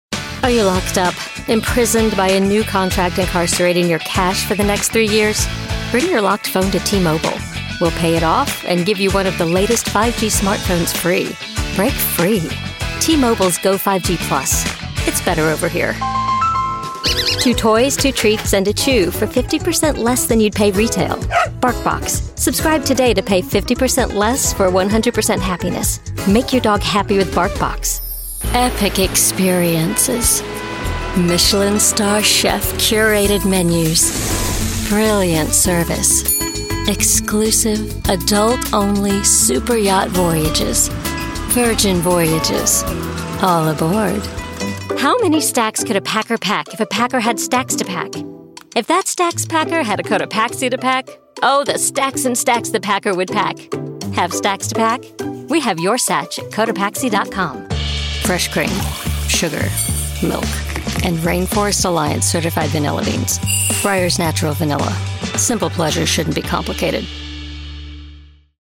English (American)
Natural, Accessible, Versatile, Friendly, Corporate
Commercial